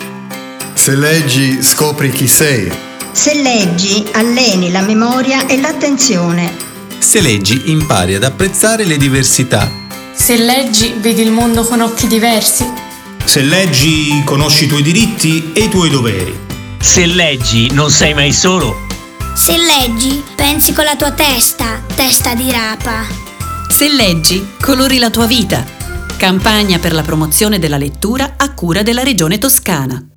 Spot radiofonico